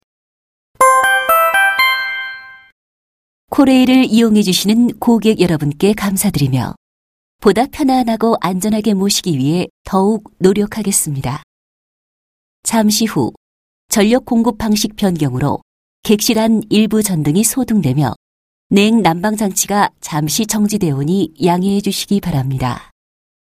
옛 한국철도공사 절연구간 안내방송